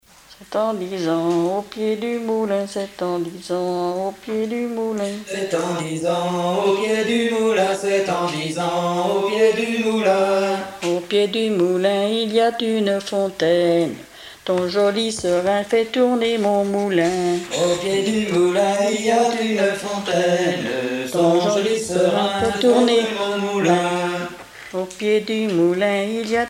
Genre énumérative
Chansons et contes traditionnels